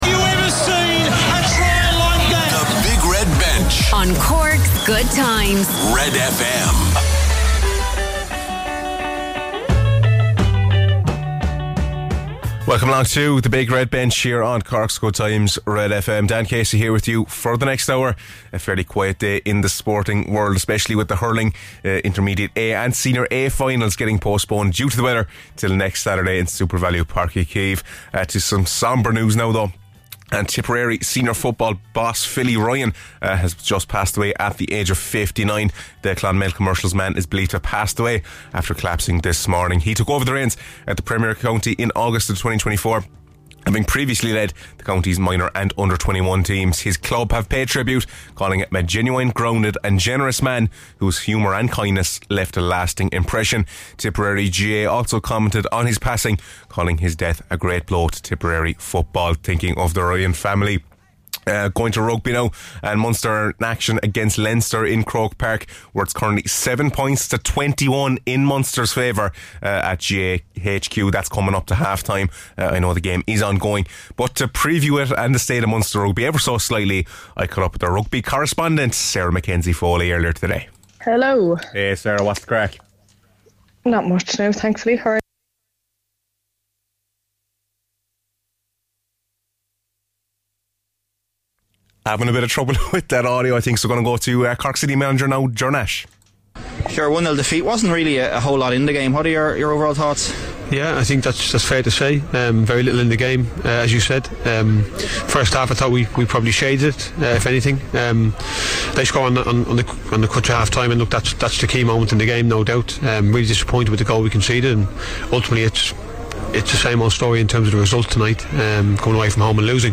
Bringing you live reaction from Sarsfield vs Ballygunner of Waterford ,Ballinora vs ilen rovers and our national soccer team with their massive win against Hungary today! Lots of chat today on the show!